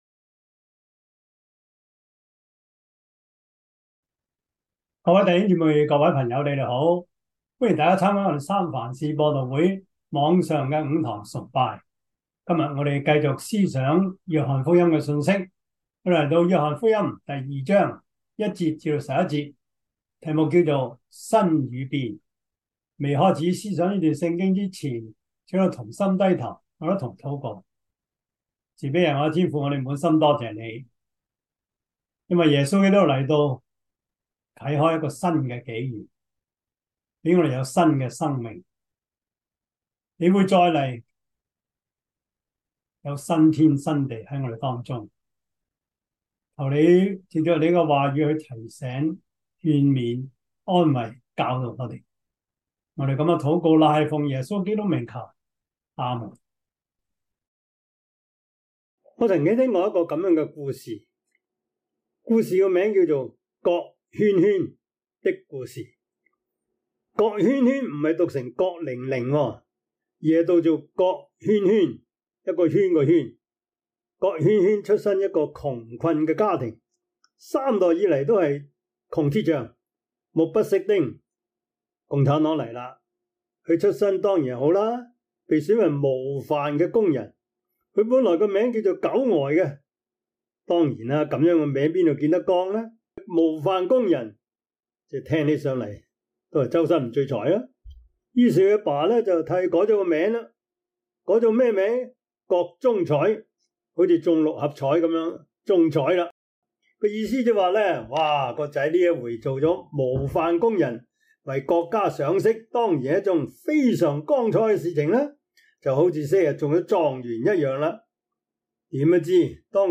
約翰福音 2:1-11 Service Type: 主日崇拜 約翰福音 2:1-11 Chinese Union Version
Topics: 主日證道 « 從金錢看靈命 – 奉獻的意義 貪之過 »